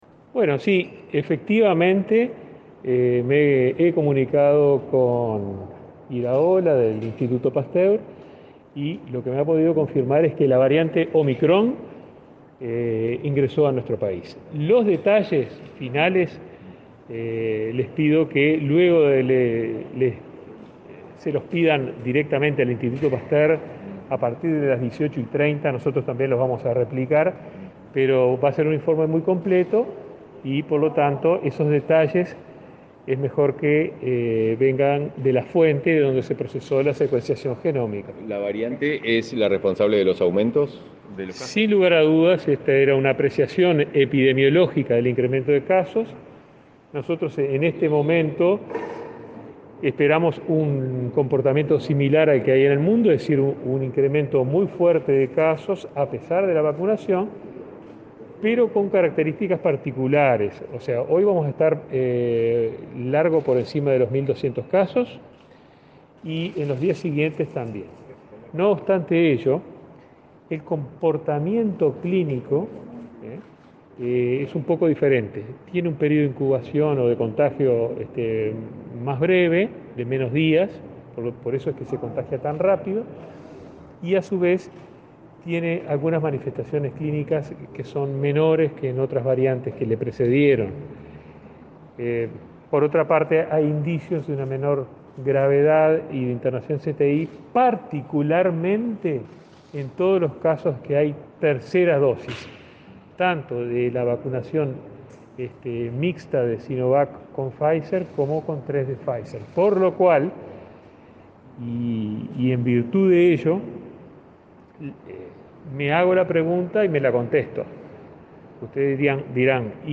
Declaraciones a la prensa del ministro y subsecretario de Salud Pública
El ministro Daniel Salinas y el subsecretario José Luis Satdjian dialogaron con la prensa tras participar del Consejo de Ministros en Torre Ejecutiva.